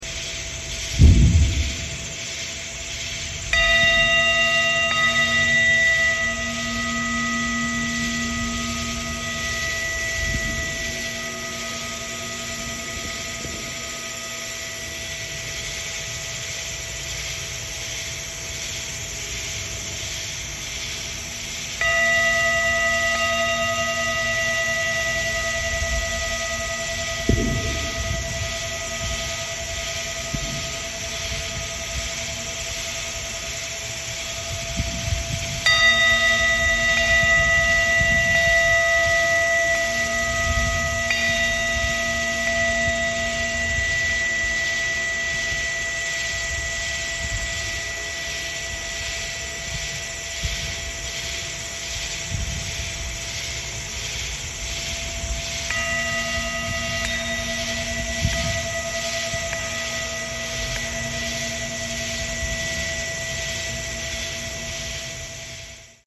Tibetan Singing Bowl